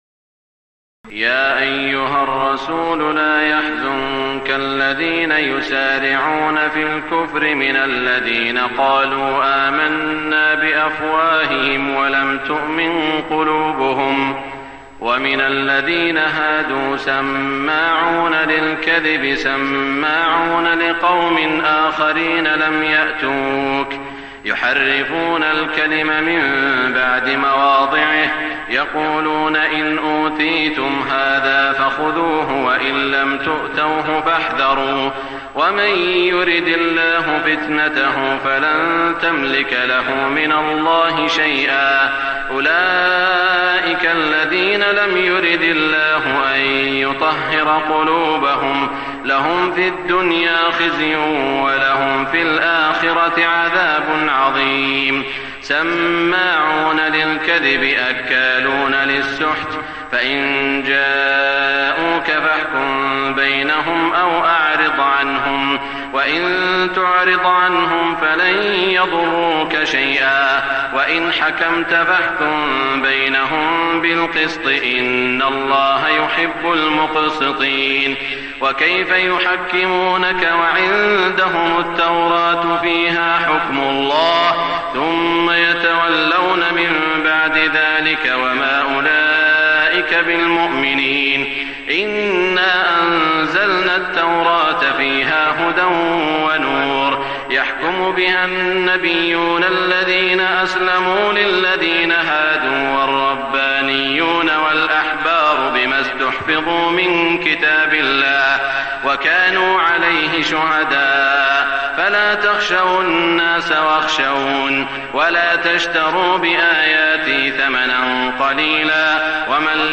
تهجد ليلة 26 رمضان 1422هـ من سورة المائدة (41-86) Tahajjud 26 st night Ramadan 1422H from Surah AlMa'idah > تراويح الحرم المكي عام 1422 🕋 > التراويح - تلاوات الحرمين